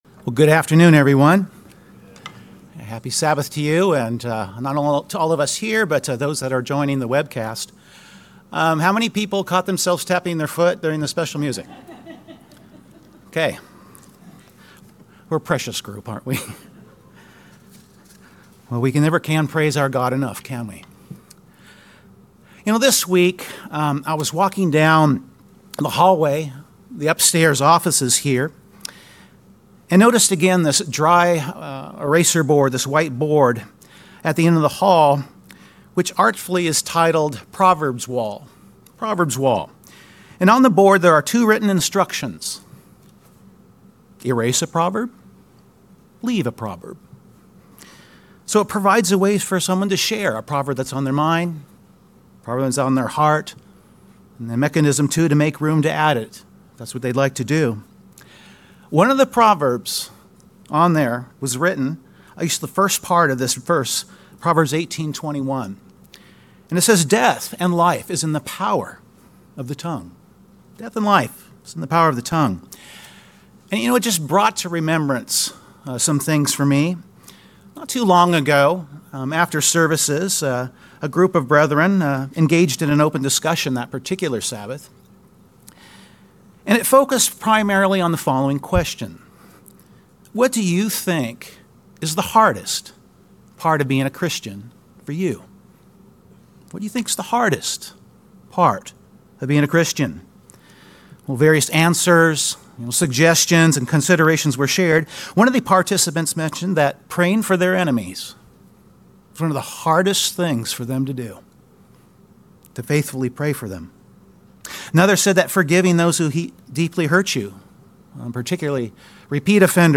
James chapter 3 contains a poignant description of what the tongue is capable of. The sermon will focus on this and other biblical examples that show us how our words are to be directed, how to follow God's lead in this, avoiding the potential pitfalls, and the importance of where our hearts are placed.